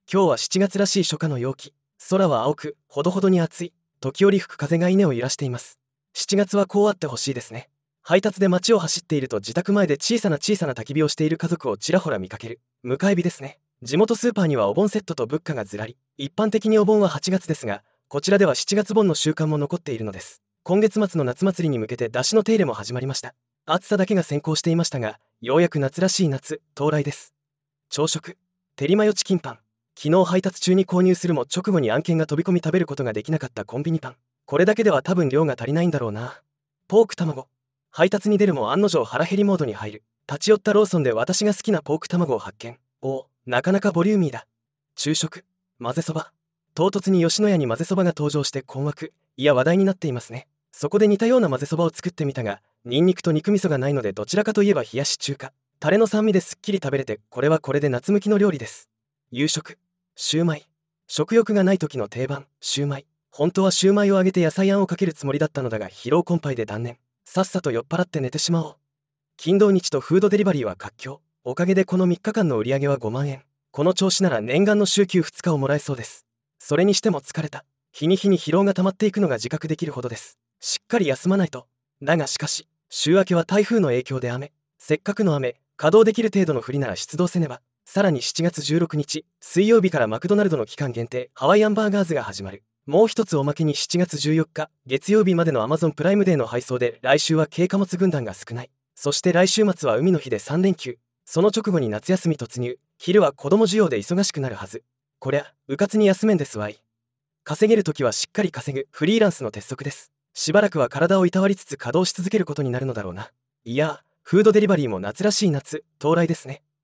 時折吹く風が稲を揺らしています。
初夏の田んぼ